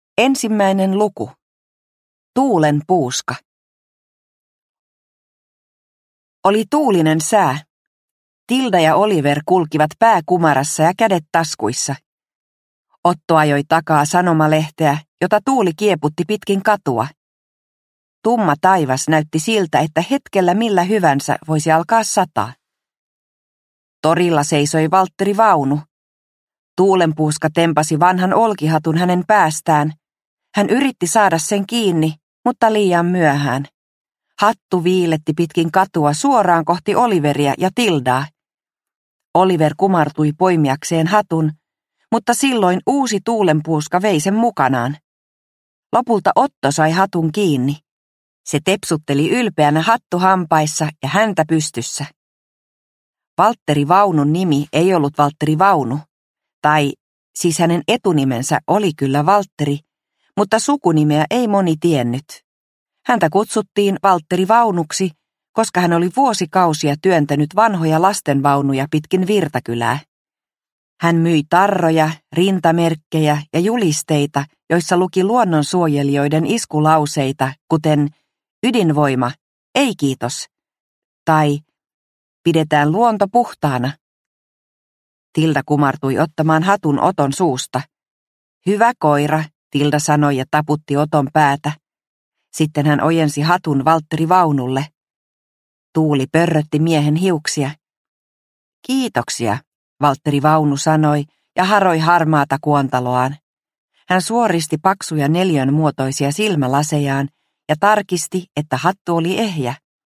Operaatio Tuulenpuuska – Ljudbok – Laddas ner